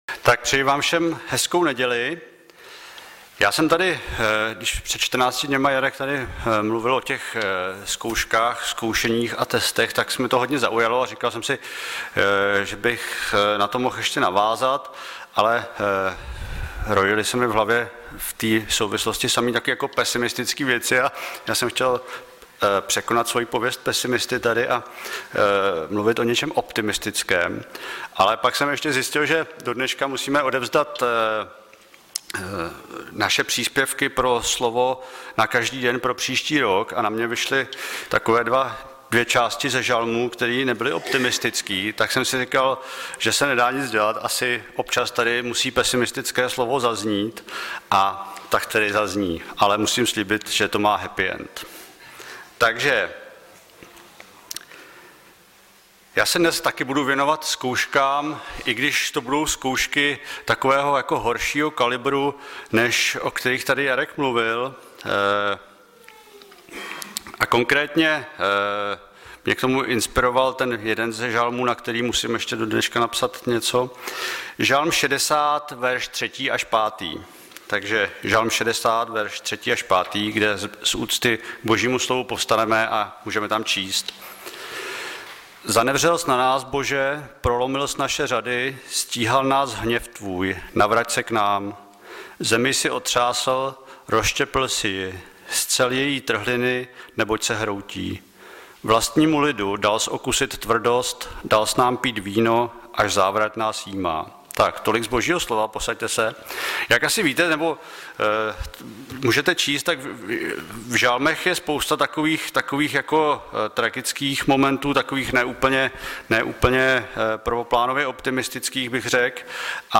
Webové stránky Sboru Bratrské jednoty v Litoměřicích.
Kázání